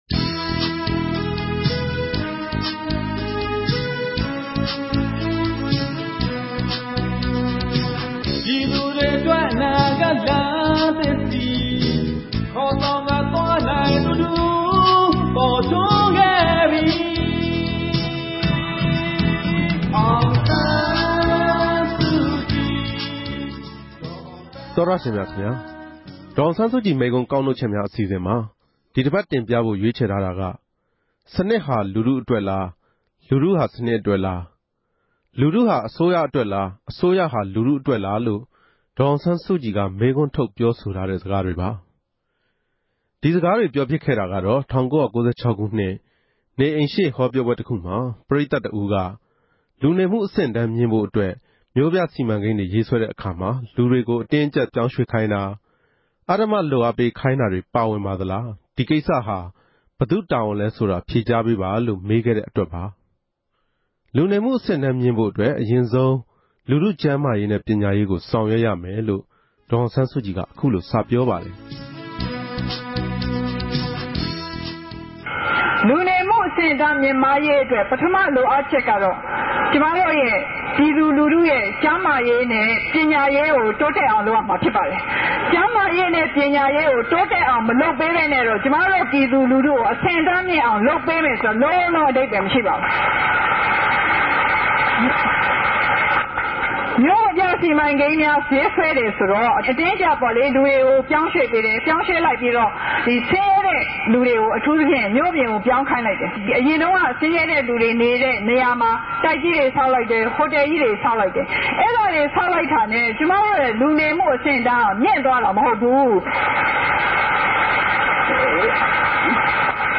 ဒေါ်အောင်ဆန်းစုကြည် မိန့်ခွန်းကောက်နှုတ်ချက်များ.